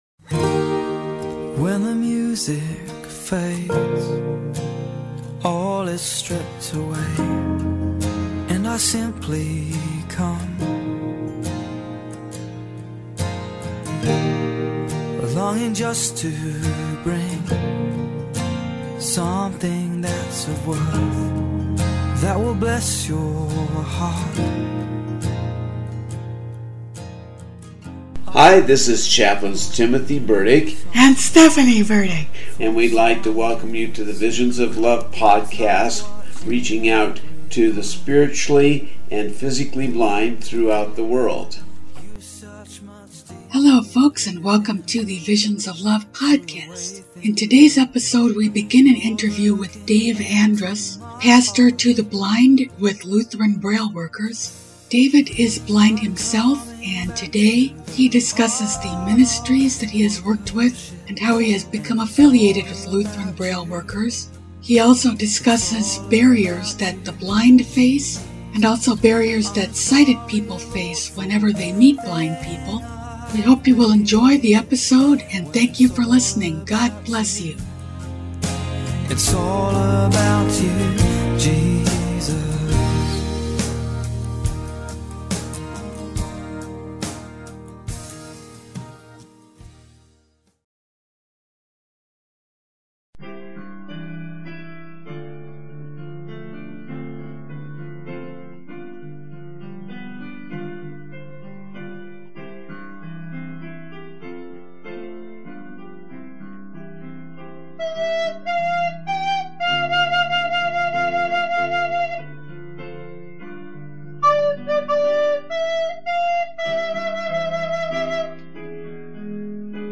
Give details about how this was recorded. We hope you'll enjoy the first half of his interview, along with the music we have played before and after the main event!